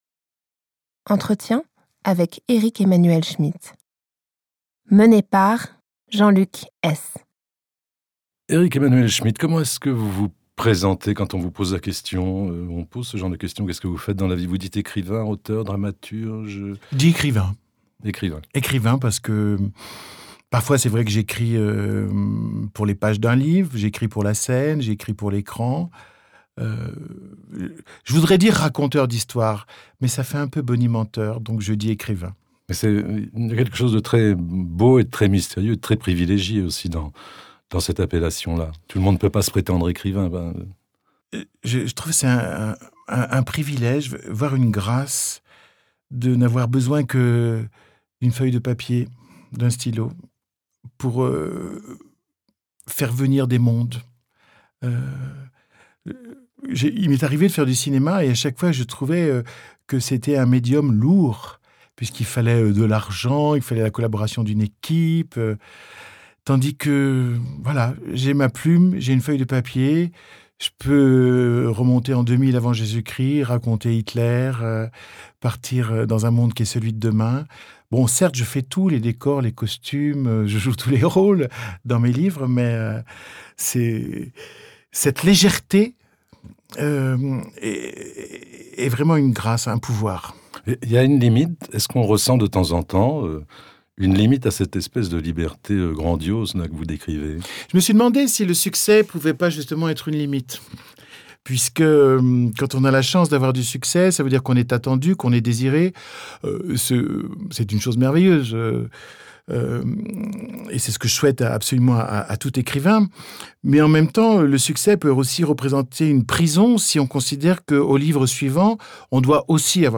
L'Ecrivain - Eric-Emmanuel Schmitt - Entretien inédit par Jean-Luc Hees